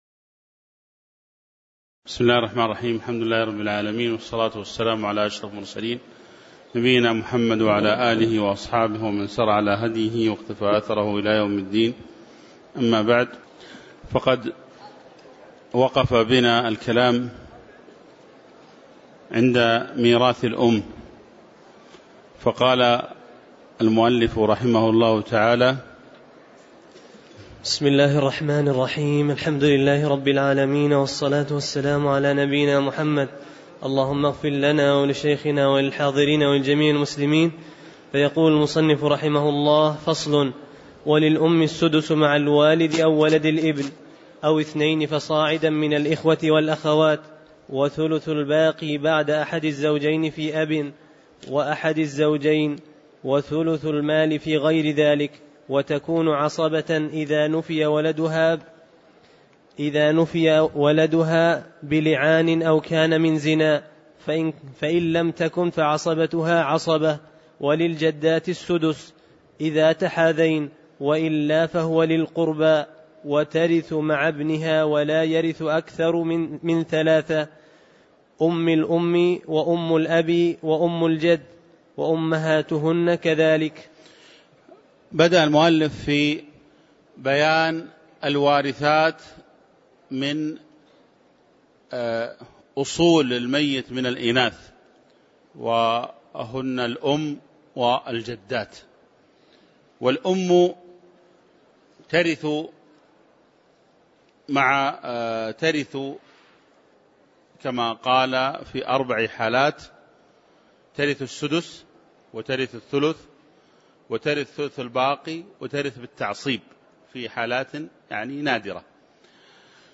تاريخ النشر ٢٠ شوال ١٤٣٩ هـ المكان: المسجد النبوي الشيخ